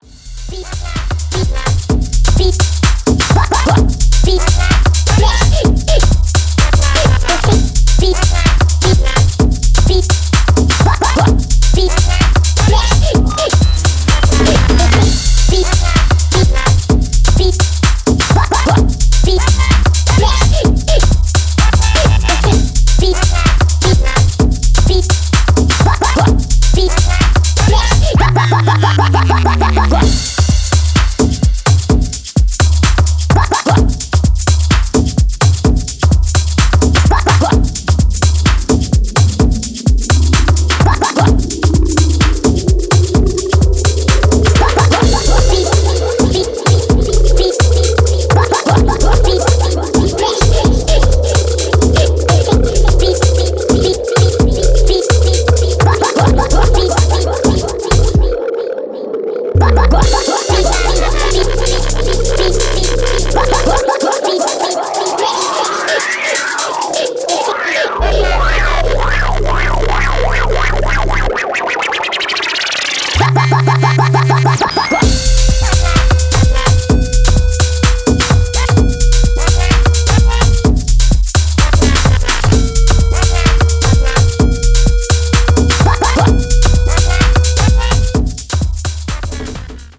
ボトムヘヴィなトライバル・グルーヴとヴォイスサンプルの混沌とした応酬